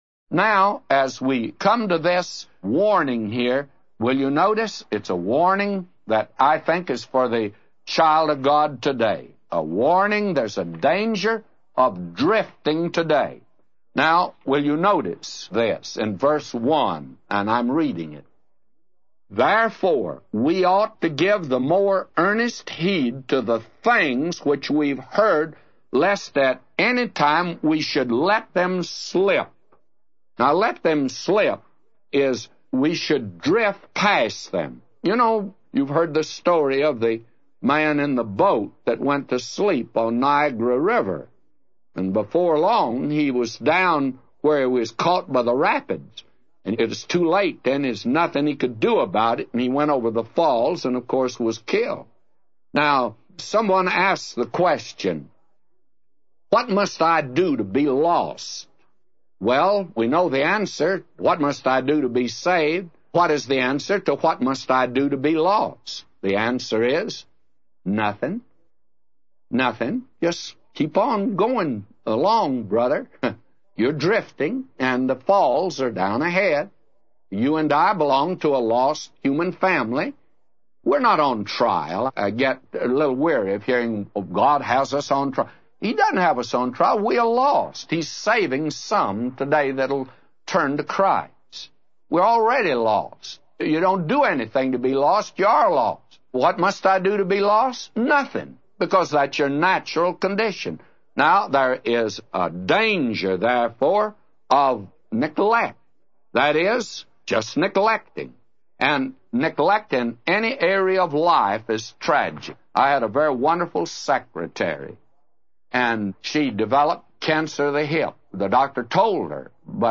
A Commentary By J Vernon MCgee For Hebrews 2:1-999